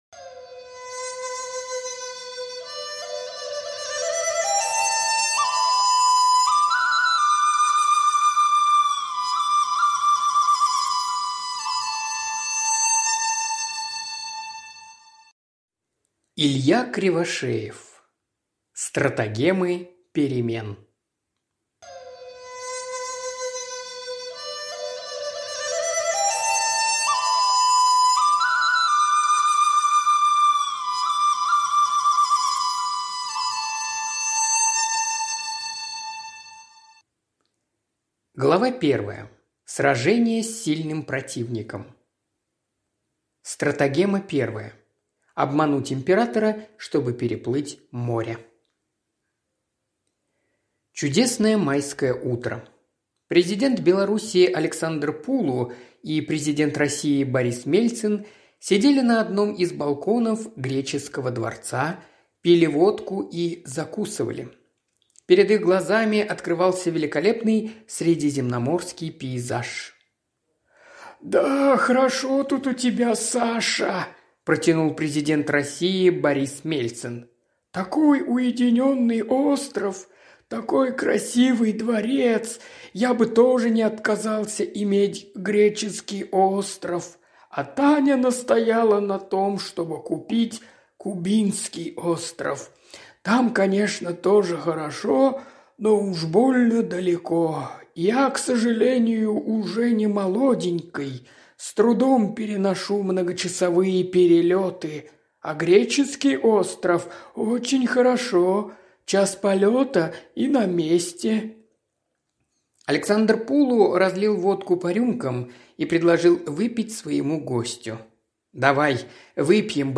Аудиокнига Стратагемы перемен | Библиотека аудиокниг